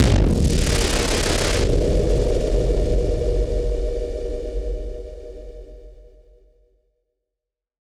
BF_SynthBomb_B-01.wav